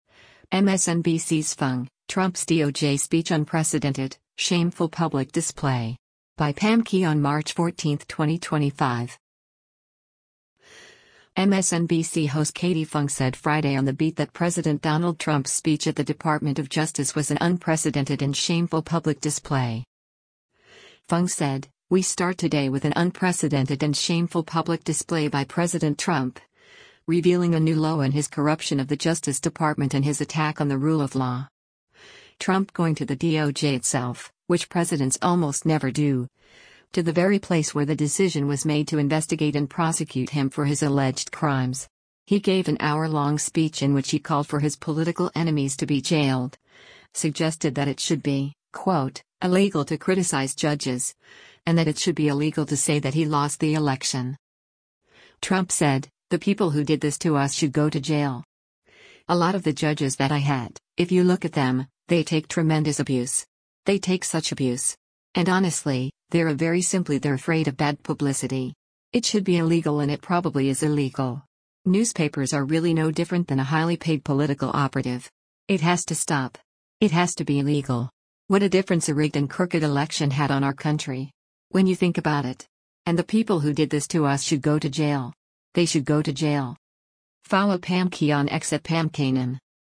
MSNBC host Katie Phang said Friday on “The Beat” that President Donald Trump’s speech at the Department of Justice was an “unprecedented and shameful public display.”